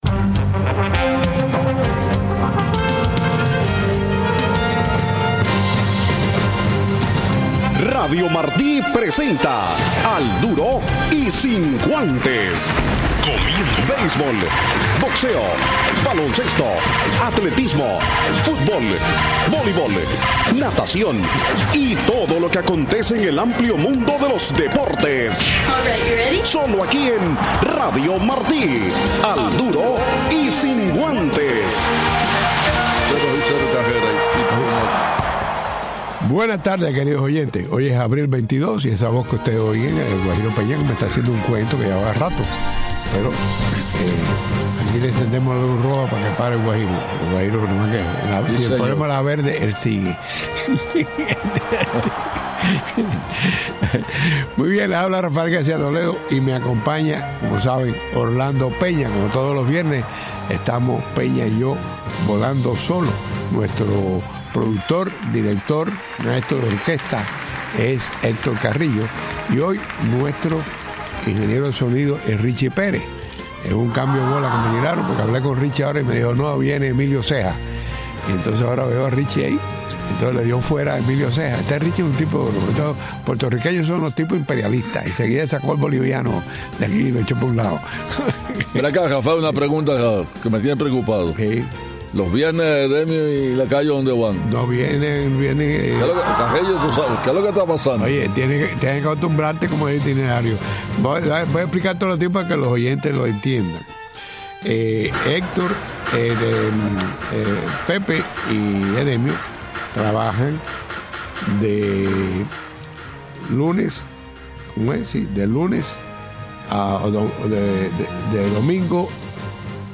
una charla deportiva.